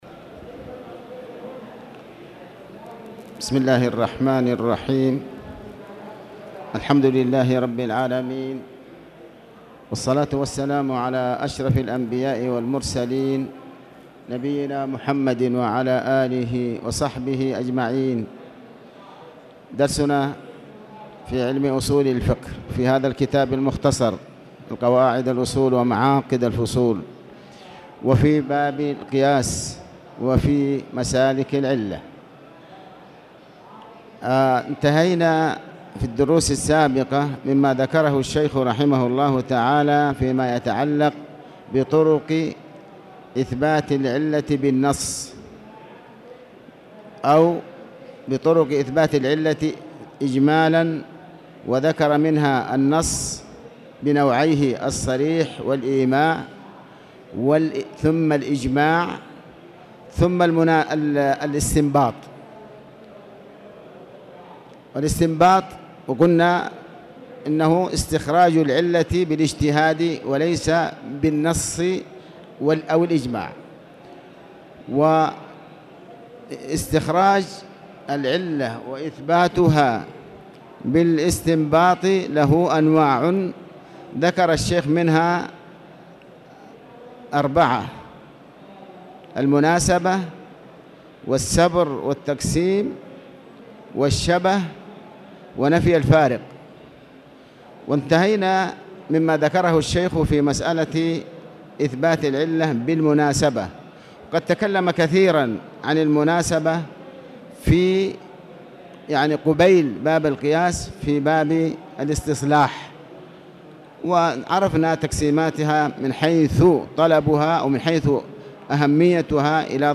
تاريخ النشر ٢٥ جمادى الأولى ١٤٣٨ هـ المكان: المسجد الحرام الشيخ: علي بن عباس الحكمي علي بن عباس الحكمي السبر والتقسيم